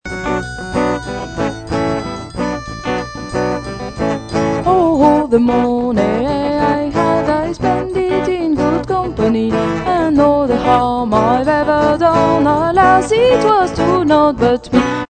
folk traditions Irlande